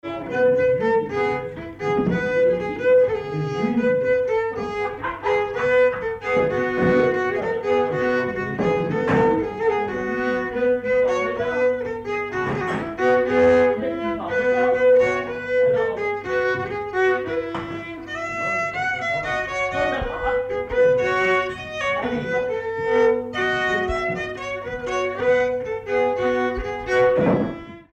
Air
Pièce musicale inédite